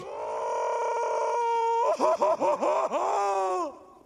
Patrick Star Screaming